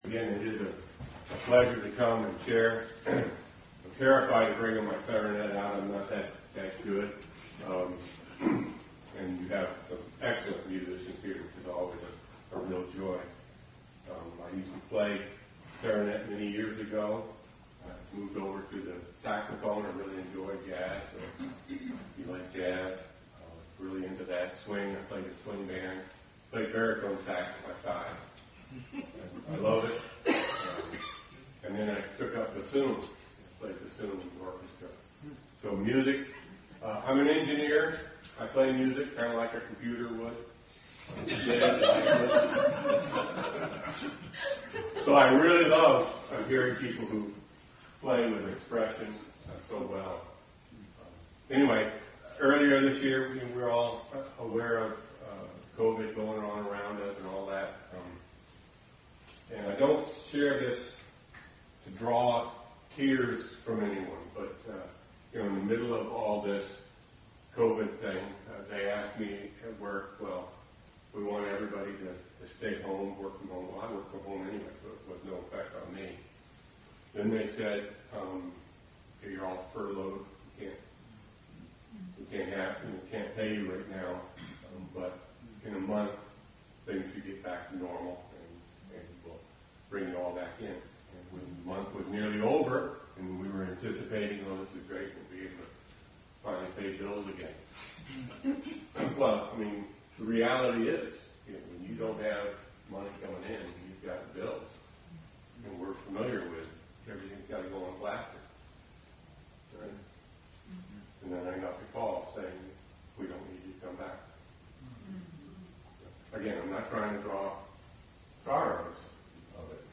Bible Text: John 6, John 20:31 | Family Bible Hour message from John 6.